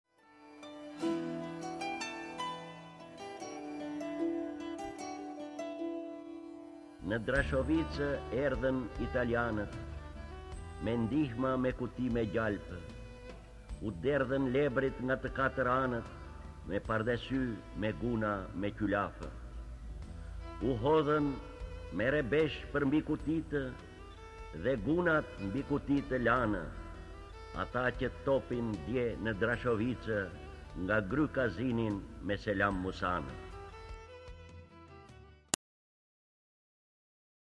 D. AGOLLI - VARFËRIA Lexuar nga D. Agolli KTHEHU...